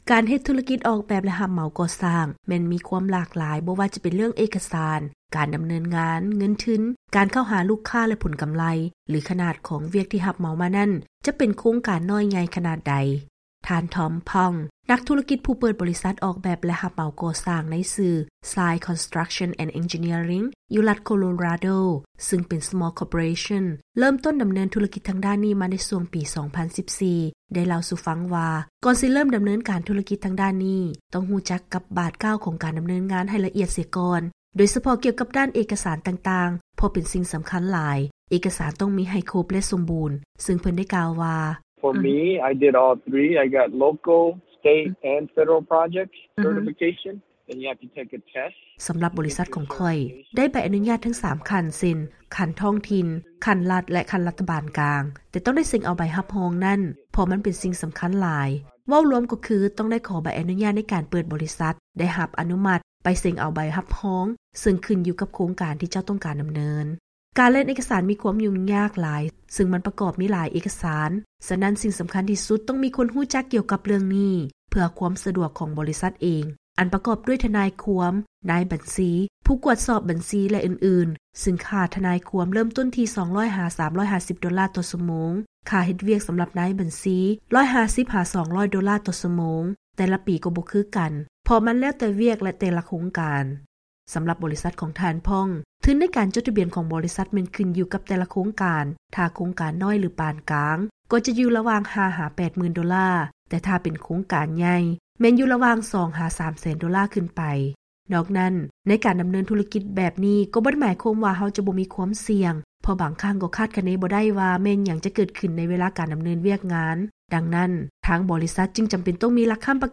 ເຊີນຟັງລາຍງານກ່ຽວກັບ ການເຮັດທຸກິດທາງດ້ານອອກແບບ ແລະຮັບເໝົາກໍ່ສ້າງ